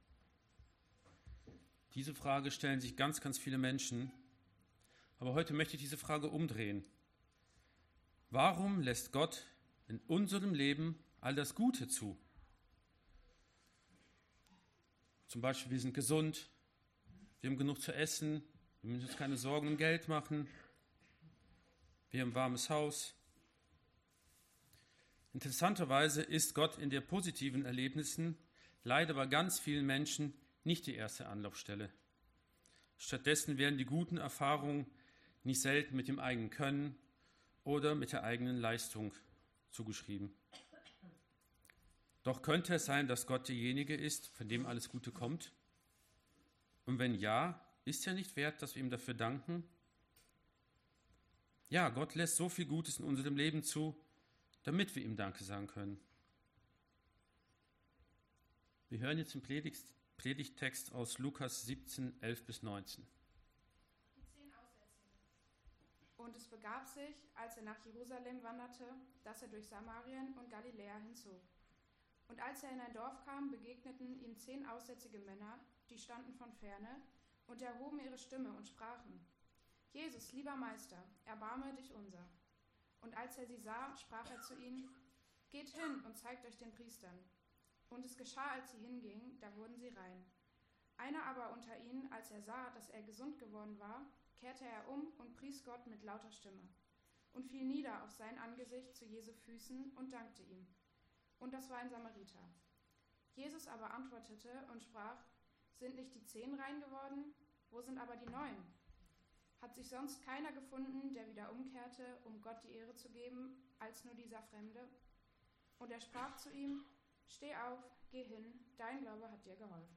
Evangelisch-Freikirchliche Gemeinde Borken - Predigten anhören